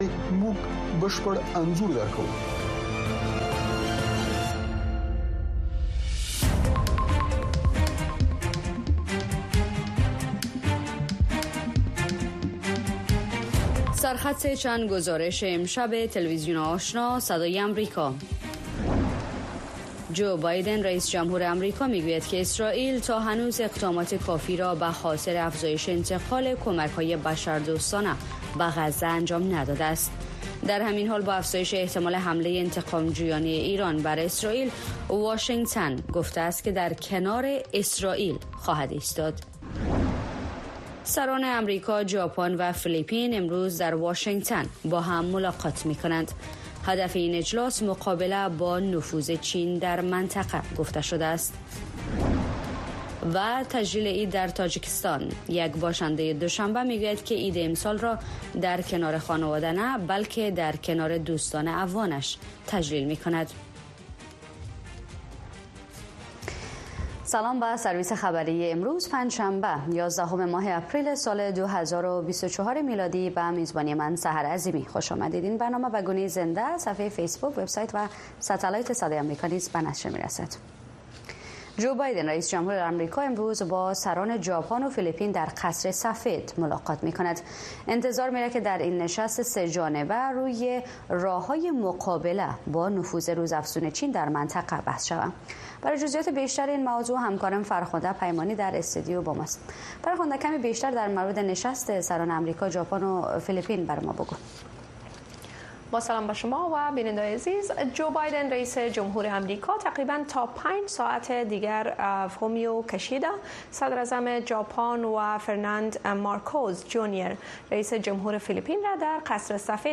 تاکید مجدد هیبت الله آخندزاده رهبر طالبان در بارۀ تطبیق حدود شرعی به شمول قصاص و سنگسار در افغانستان، نگرانی زنان از محدودیت های روزافزون طالبان، گزارش های زنده از کانادا، کالیفورنیا و پاکستان در بارۀ چگونگی تجلیل عید، آمادگی های عیدی در کابل و آهنگ عیدی در برنامۀ تلویزیون آشنا گنجانیده شده است.